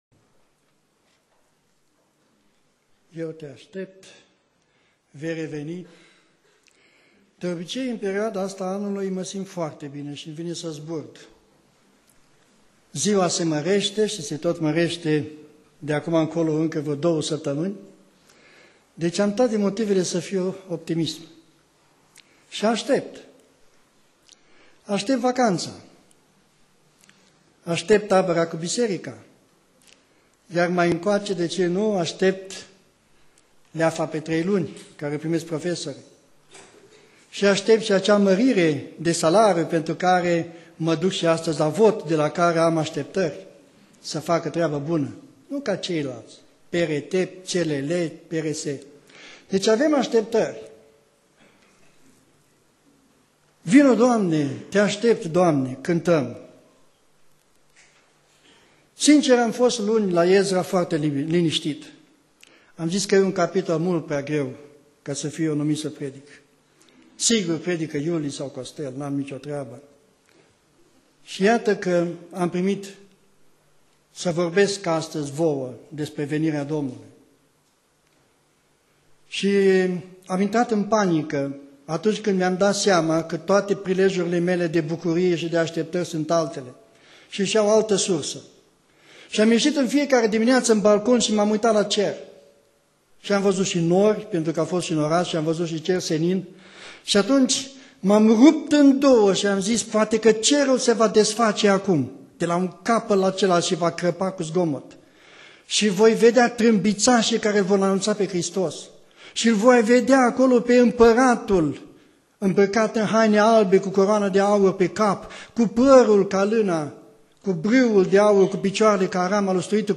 Predica Aplicatie - Matei 24